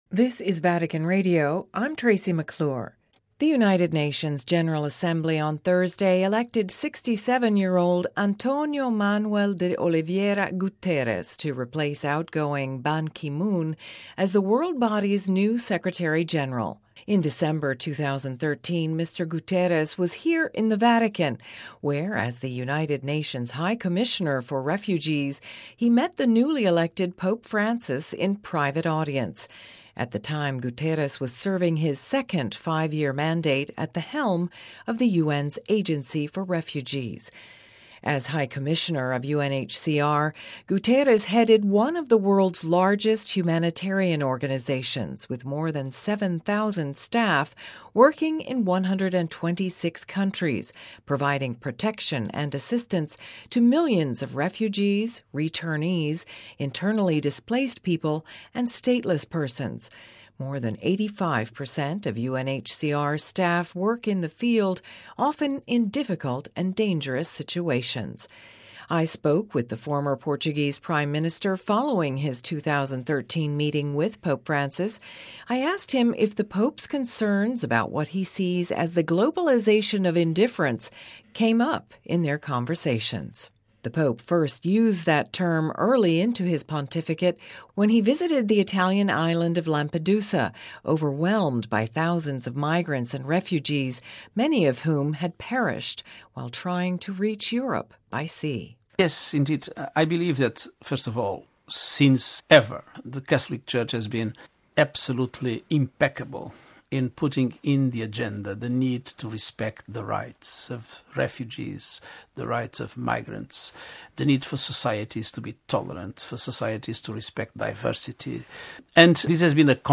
UN appoints A. Guterres new Secretary General: VR interview